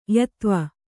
♪ yatva